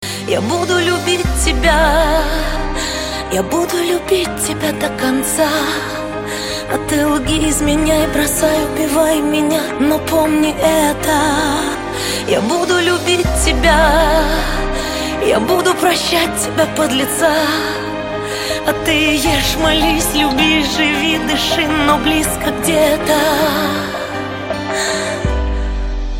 поп
женский вокал
лирика
романтичные